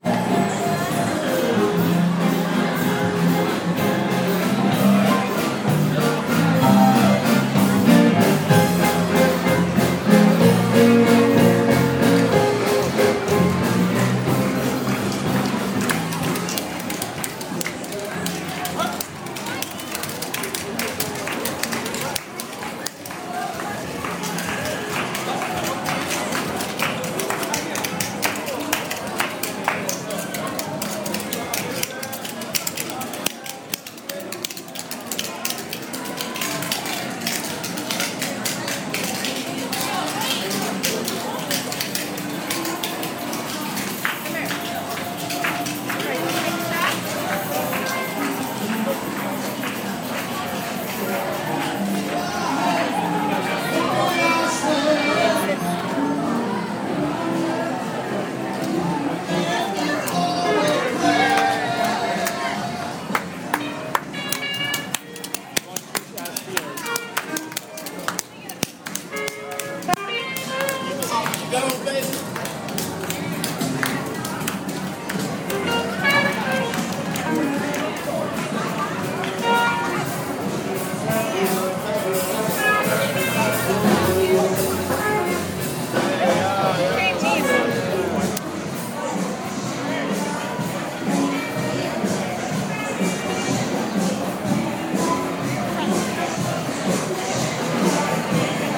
Walking through New Orleans